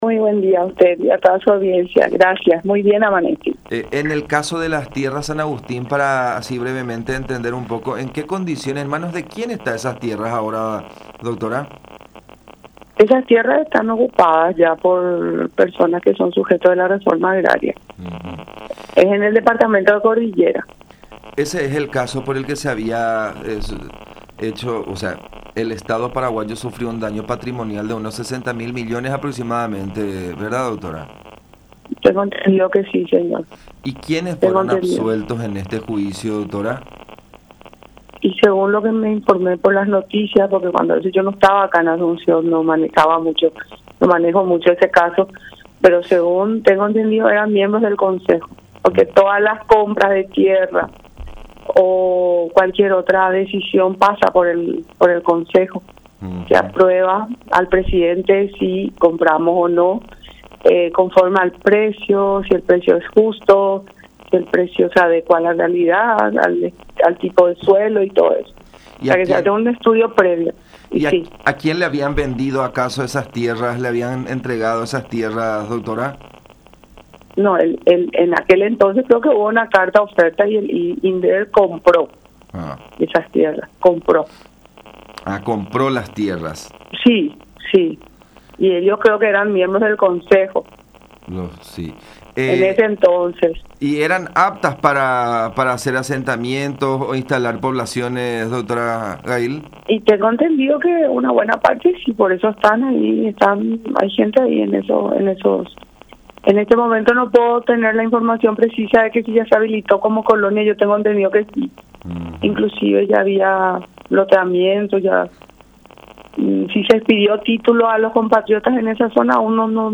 “Las 1.748 hectáreas de Marina Cué son propiedad del Ministerio del Ambiente porque así se transfirió en el año 2004, pero de esas tierras 800 ya estaban siendo utilizadas desde antes de la promulgación de la ley de Deforestación Cero”, dijo González en diálogo con La Unión.